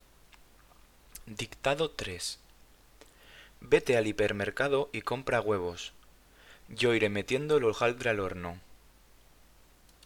Dictado 3